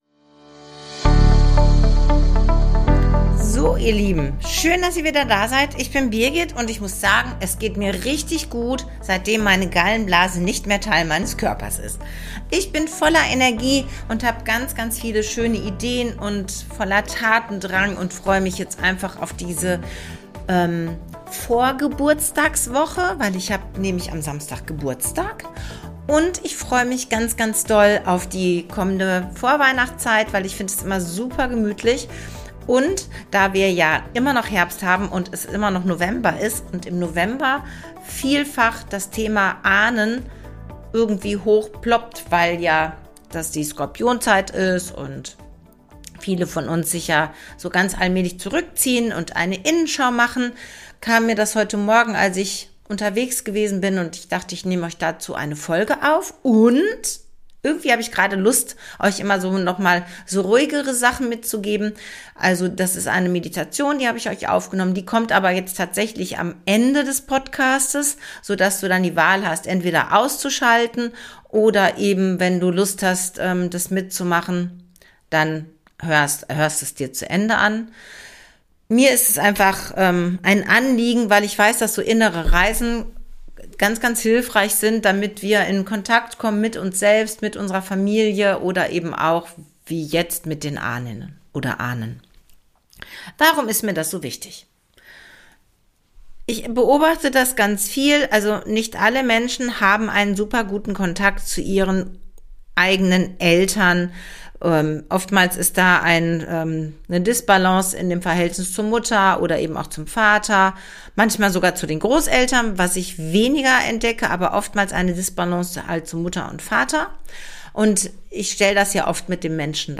Deine Vorfahren sind Teil deines Systems und gerade deshalb ist es schlau, sich auch mit dieser Kraft zu verbinden. Dazu habe ich euch am Ende noch eine kleine geführte Reise aufgenommen.